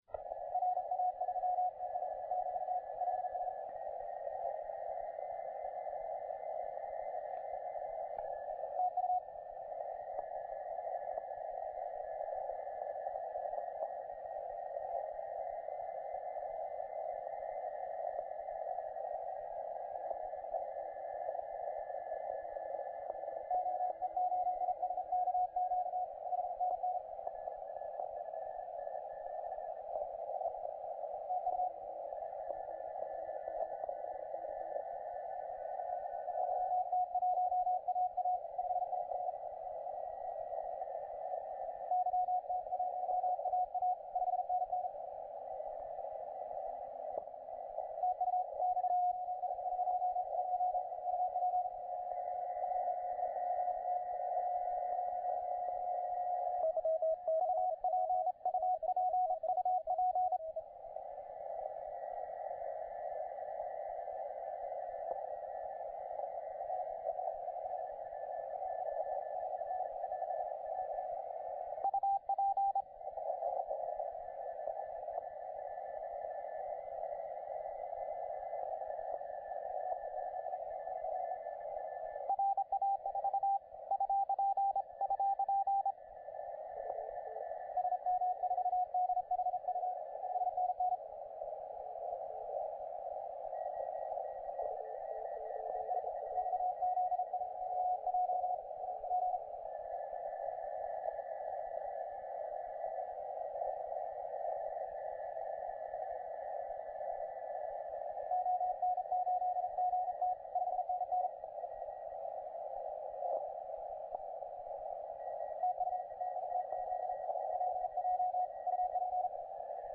XZ1J 40CW